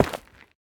Minecraft Version Minecraft Version latest Latest Release | Latest Snapshot latest / assets / minecraft / sounds / block / netherrack / break2.ogg Compare With Compare With Latest Release | Latest Snapshot